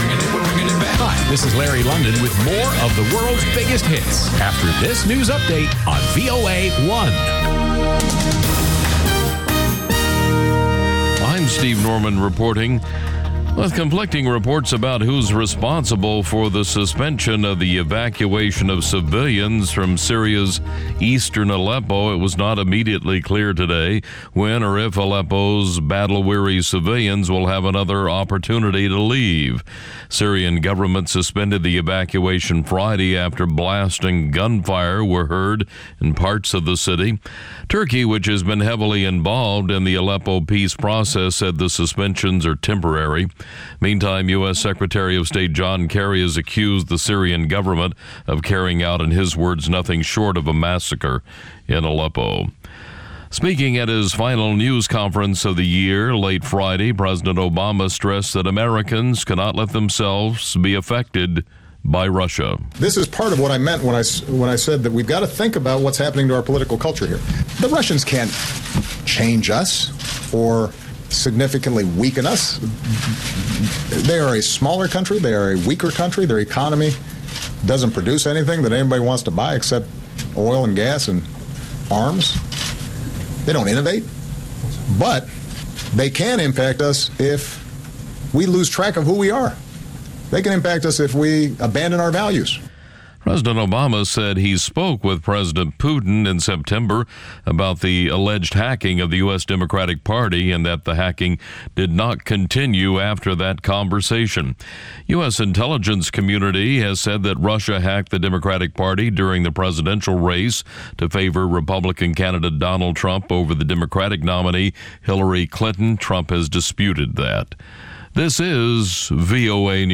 ყოველ შაბათს რადიო თავისუფლების პირდაპირ ეთერში შეგიძლიათ მოისმინოთ სპორტული გადაცემა „მარათონი“.